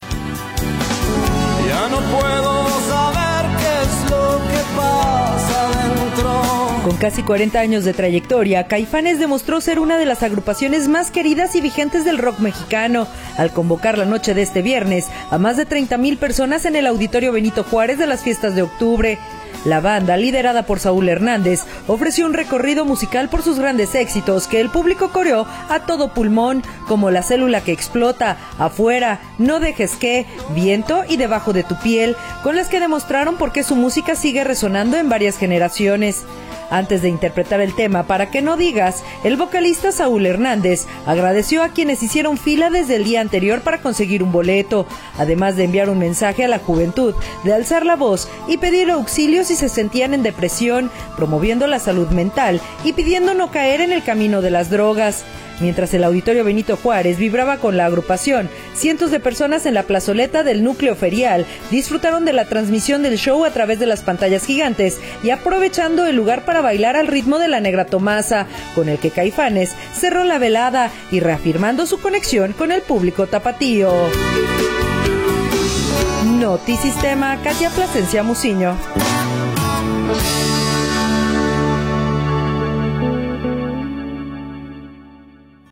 rock mexicano
que el público coreó a todo pulmón